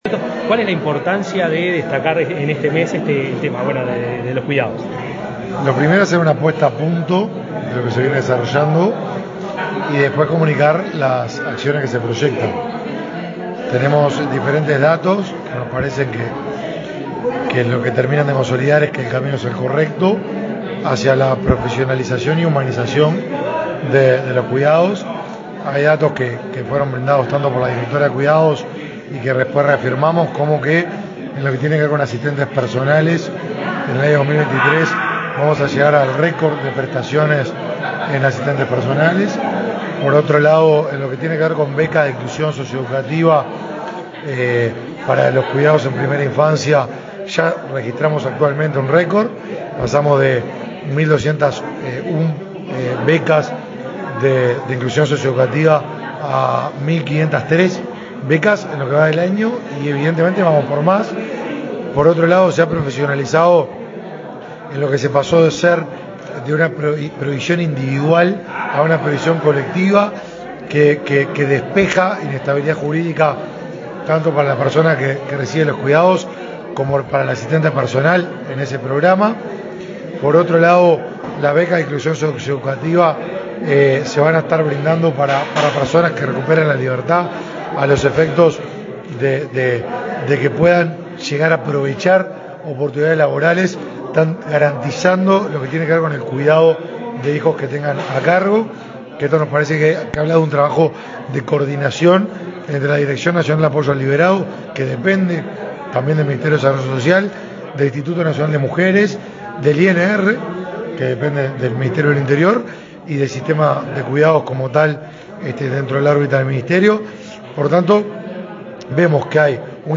Declaraciones a la prensa del ministro de Desarrollo Social, Martín Lema
El ministro de Desarrollo Social, Martín Lema, lanzó este jueves 13 el Mes de los Cuidados. Luego dialogó con la prensa.